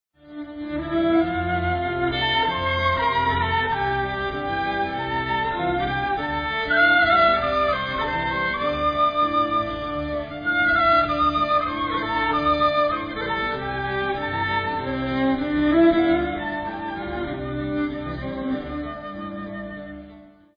Audio samples are low resolution for browsing speed.